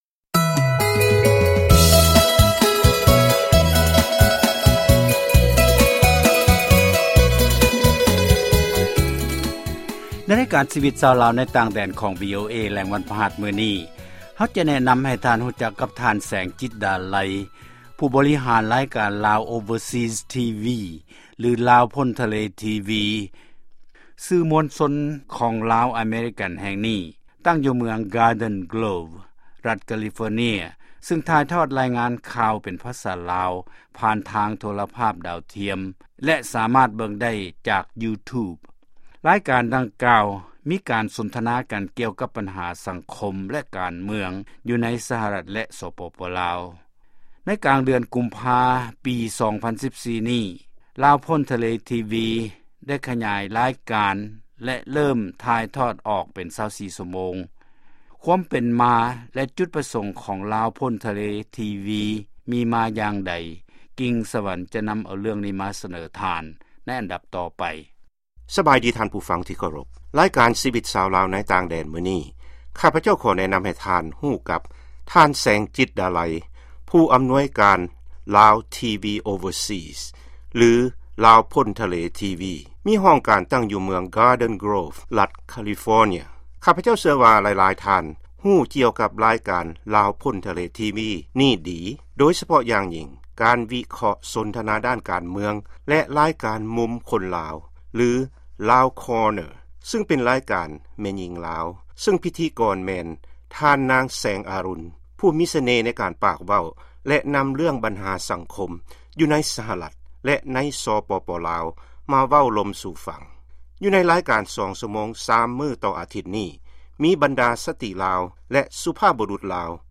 ຟັງລາຍການ ສໍາ ພາດ ຜູ້ບໍລິຫານ ລາວພົ້ນທະເລ ທີວີ